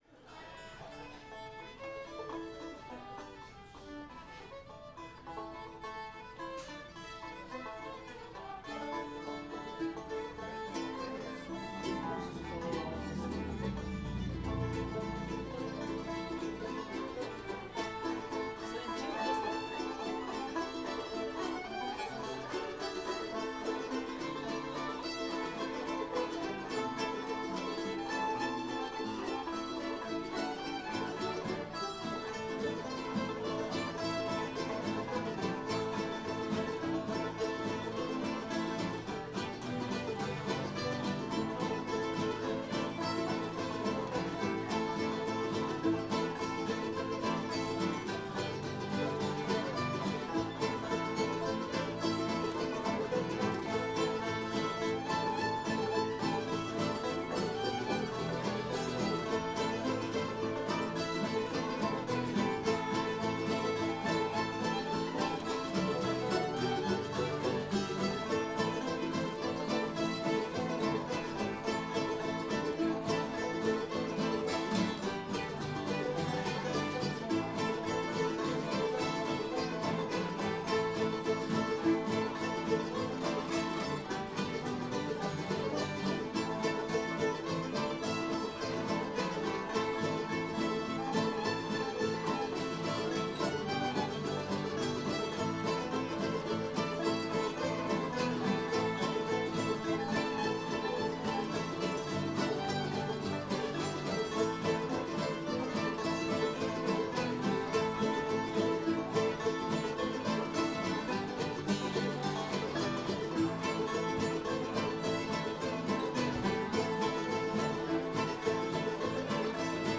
cold frosty morning [A modal]